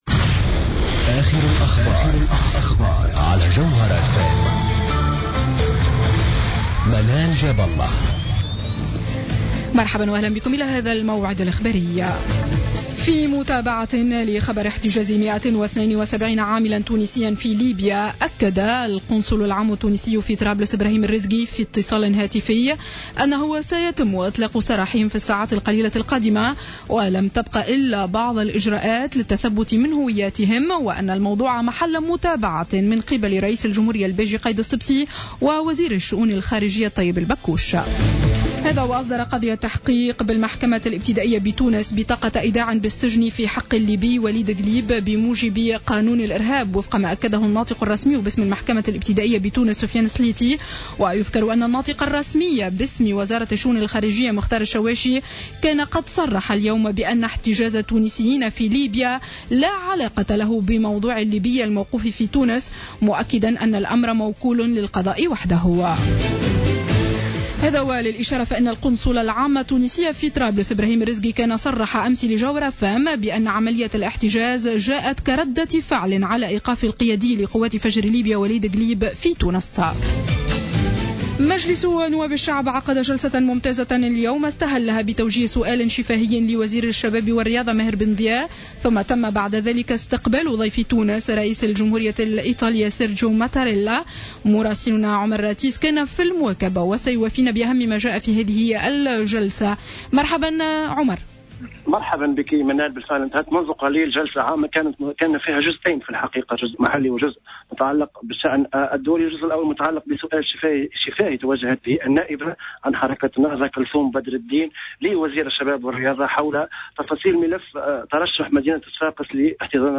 نشرة أخبار السابعة مساء ليوم الاثنين 18 ماي 2015